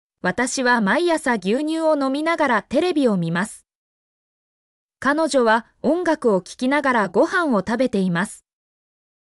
mp3-output-ttsfreedotcom-30_e2lstRpz-1.mp3